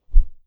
Close Combat Swing Sound 23.wav